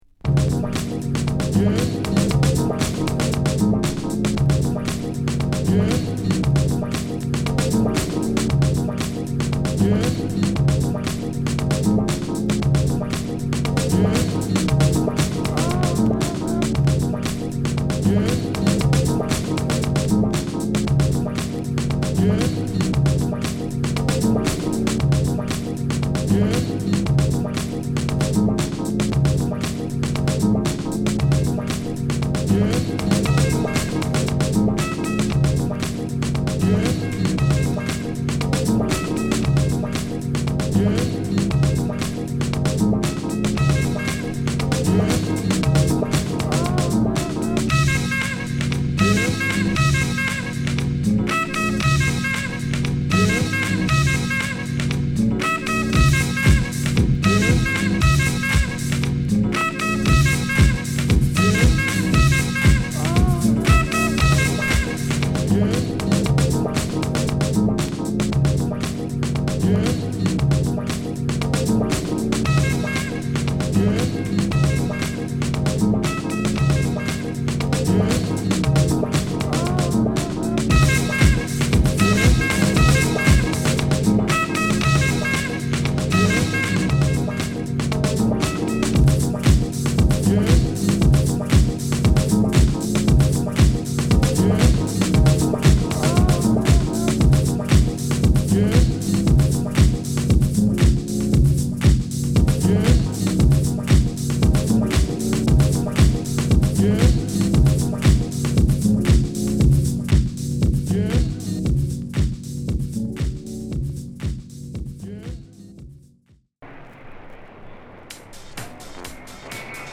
Deep House
Sampling Beats